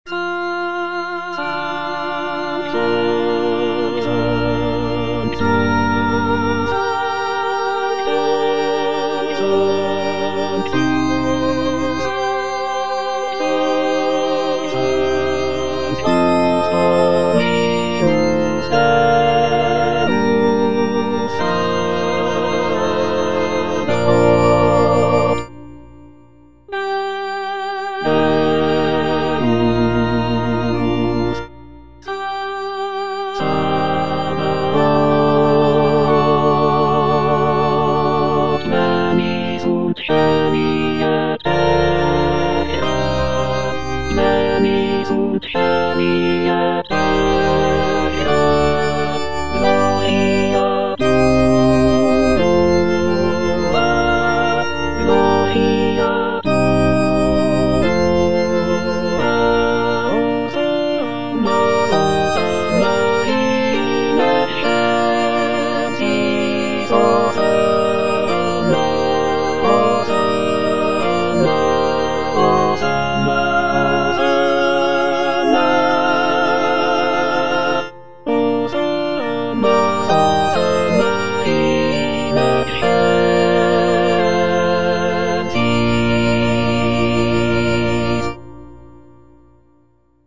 (All voices)
choral work